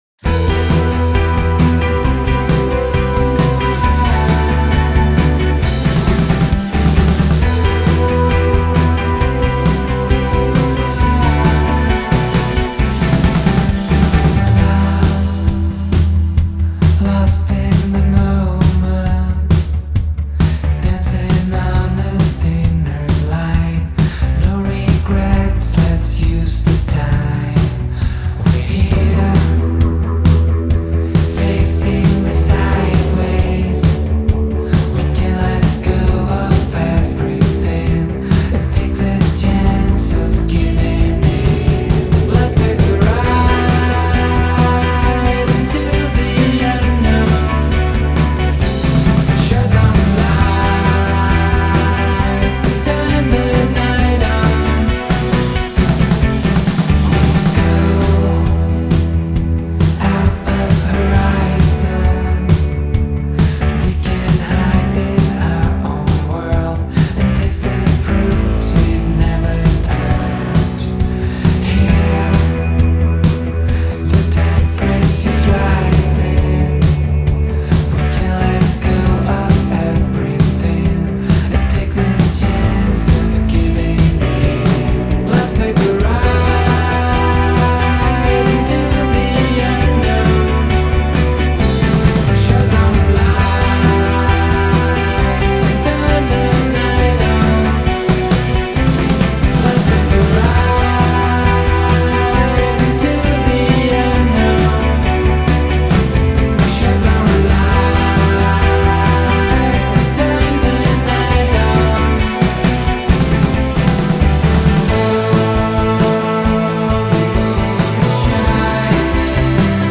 Genre: Electronic, Indie Pop